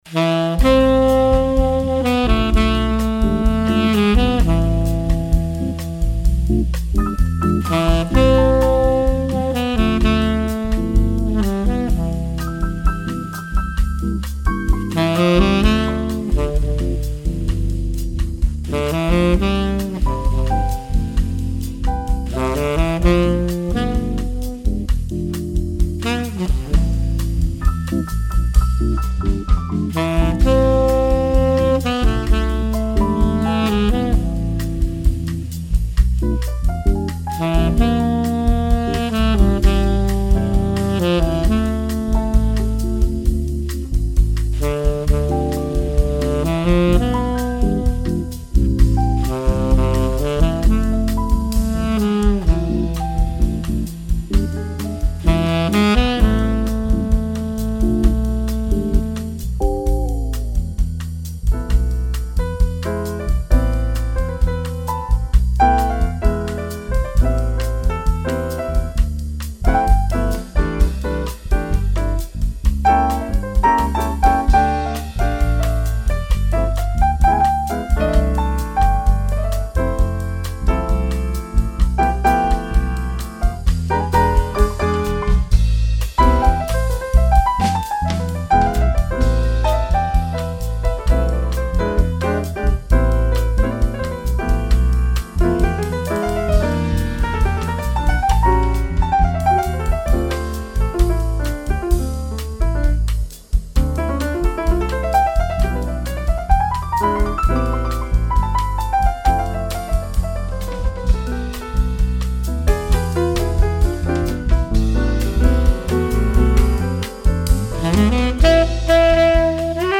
A bossa standard recorded in Goshen